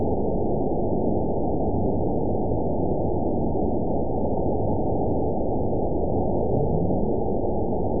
event 920519 date 03/28/24 time 16:53:07 GMT (1 year, 1 month ago) score 9.32 location TSS-AB03 detected by nrw target species NRW annotations +NRW Spectrogram: Frequency (kHz) vs. Time (s) audio not available .wav